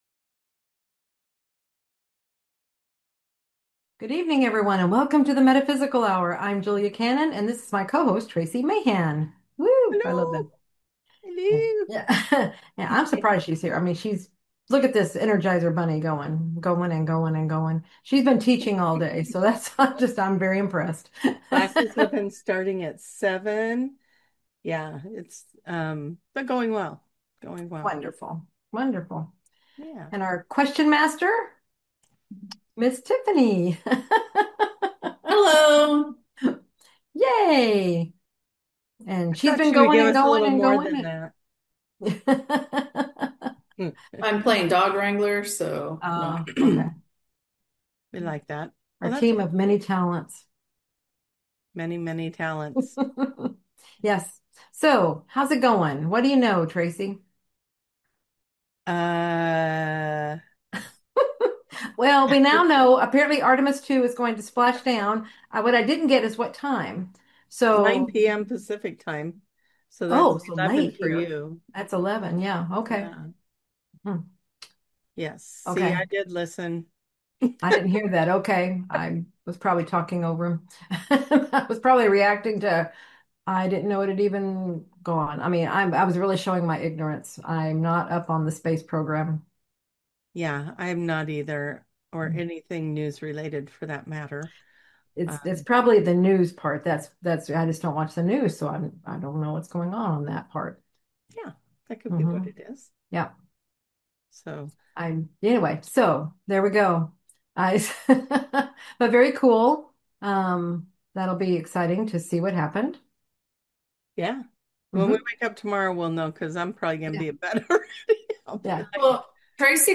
Talk Show Episode, Audio Podcast, The Metaphysical Hour and Multidimensionality, and the Evolution of Consciousness on , show guests , about QHHT Origins,Multidimensionality,Evolution of Consciousness,Current Energetic Shifts,Animal Consciousness,Quantum Healing Hypnosis Technique,Mercury Retrograde,Arcturian light codes, categorized as Earth & Space,Health & Lifestyle,Paranormal,Physics & Metaphysics,Science,Self Help,Society and Culture,Spiritual,Psychic & Intuitive